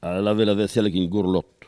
Elle provient de Saint-Jean-de-Monts.
Locution ( parler, expression, langue,... )